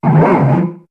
Cri de Judokrak dans Pokémon HOME.